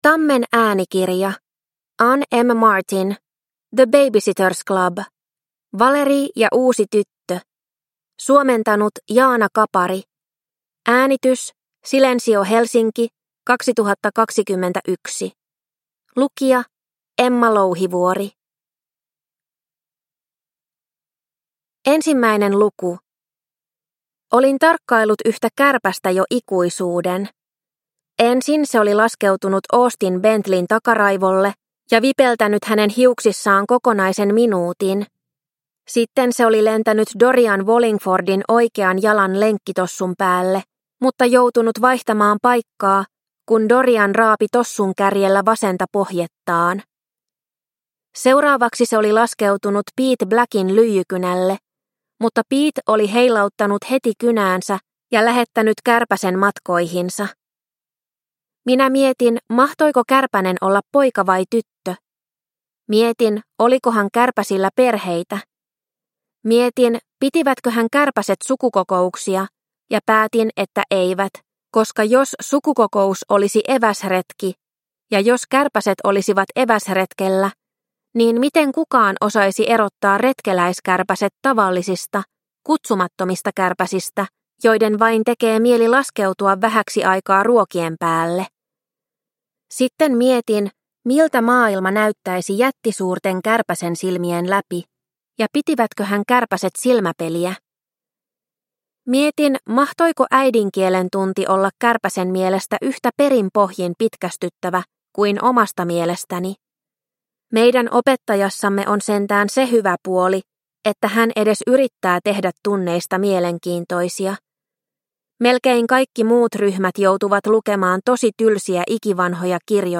The Baby-Sitters Club. Valerie ja uusi tyttö – Ljudbok – Laddas ner